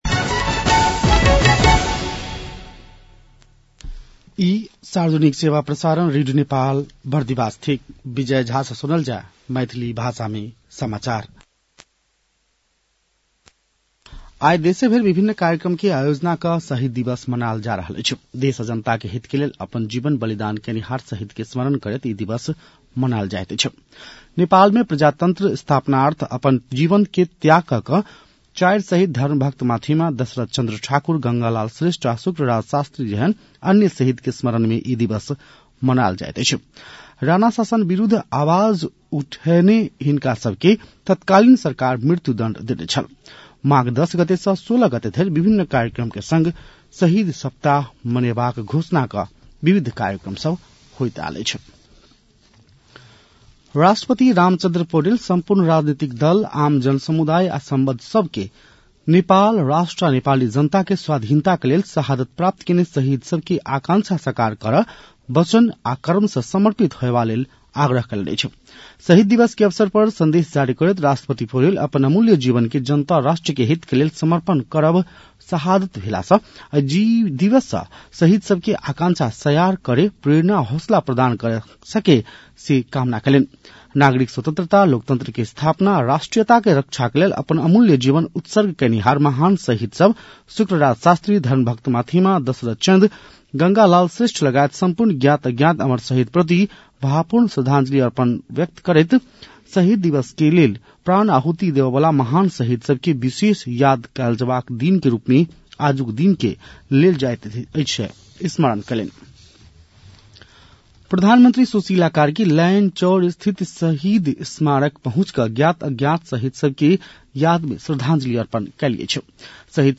मैथिली भाषामा समाचार : १६ माघ , २०८२
Maithali-news-10-16.mp3